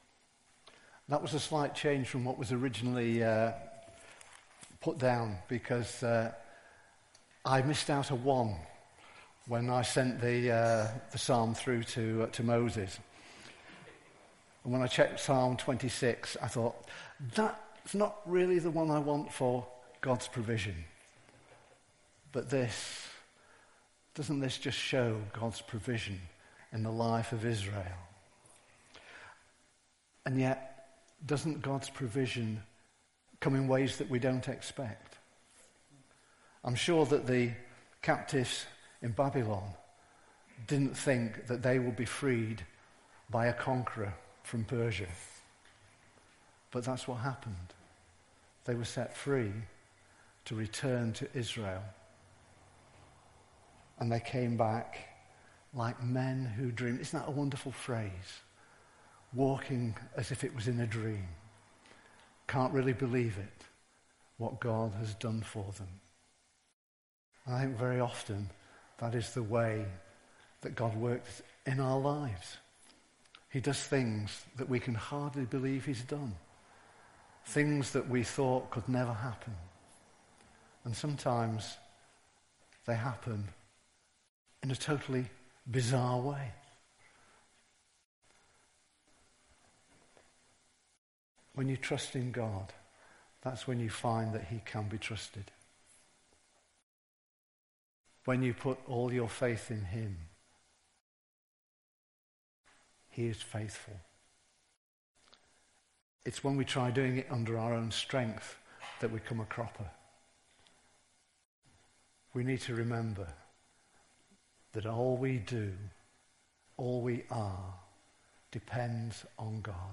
Service Type: All Age Worship
The talk is also available as an audio file but the sound goes very quiet towards the end so you may need to turn your volume up.
04-06-sermon.mp3